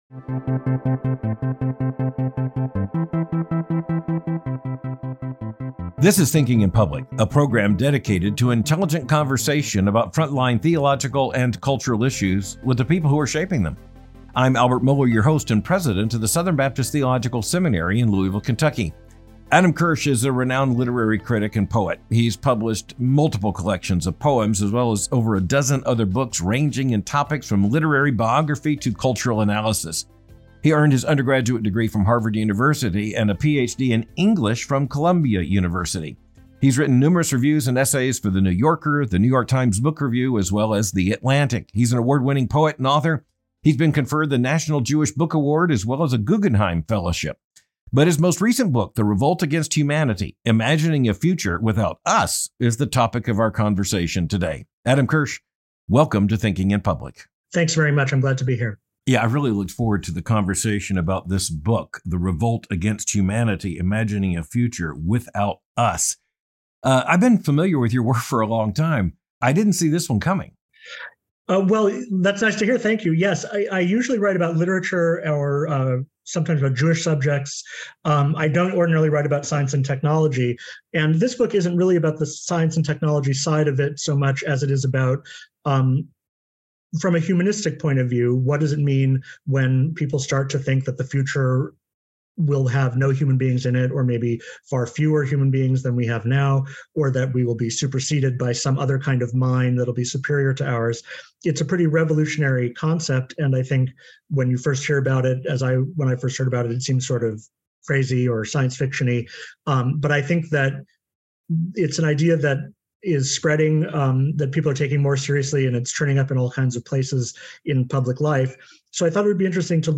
The New Religion of Artificial Intelligence and Its Threat to Human Dignity — A Conversation with Adam Kirsch